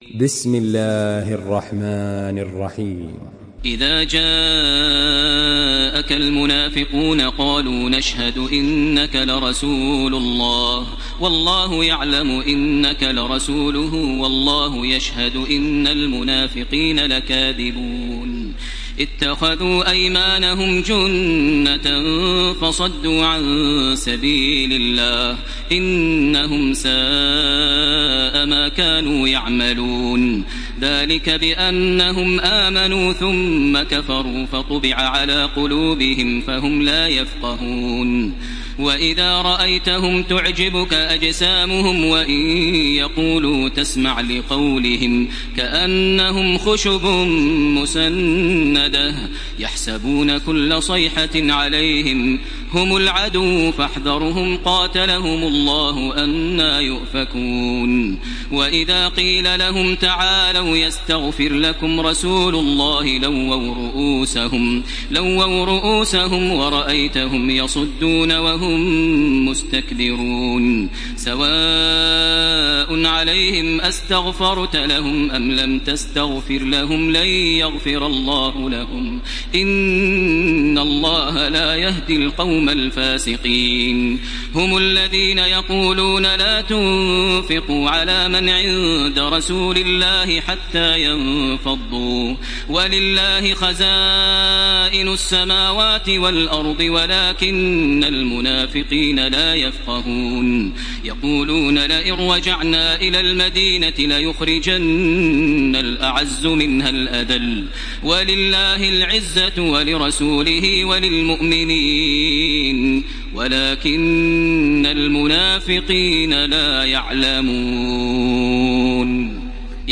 تحميل سورة المنافقون بصوت تراويح الحرم المكي 1434
مرتل